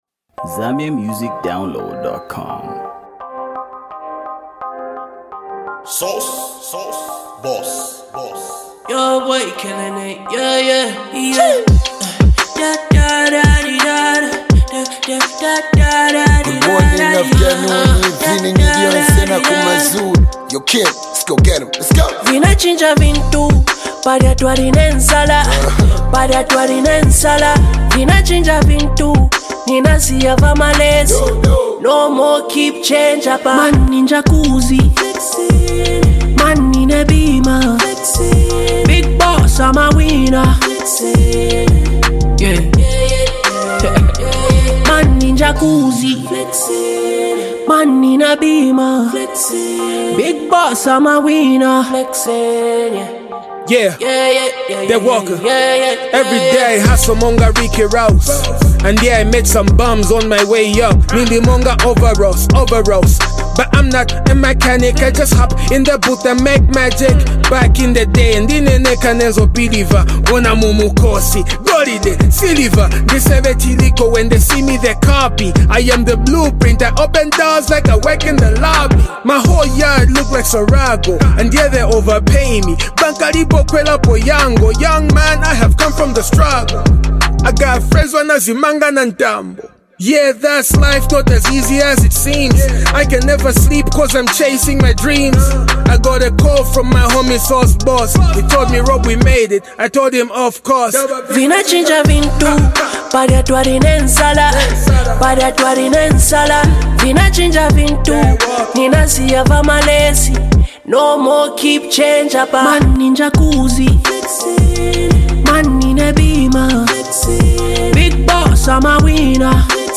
a harmonious sonic masterpiece